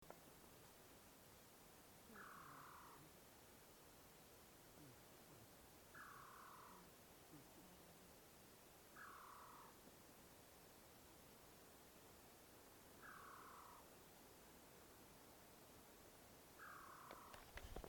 Surucuá Amarillo (Trogon chrysochloros)
Nombre en inglés: Atlantic Black-throated Trogon
Localidad o área protegida: Parque Provincial Cruce Caballero
Condición: Silvestre
Certeza: Observada, Vocalización Grabada